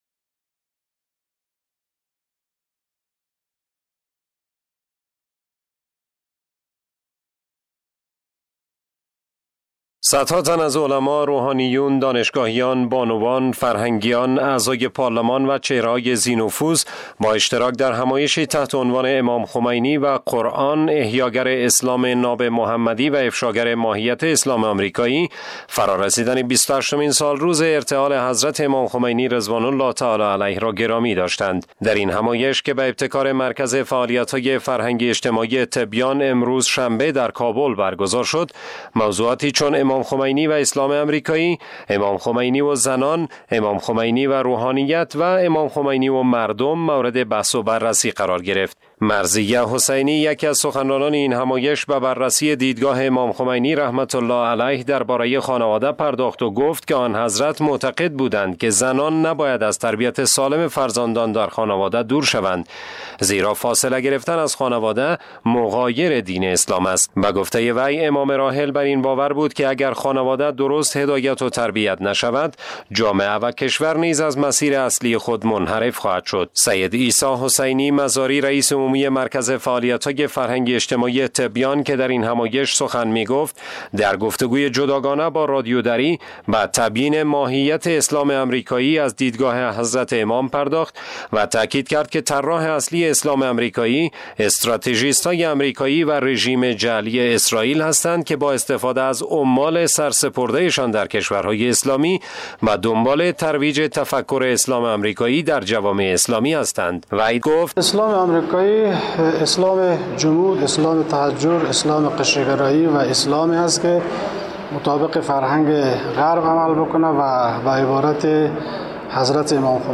به گزارش خبرنگار رادیو دری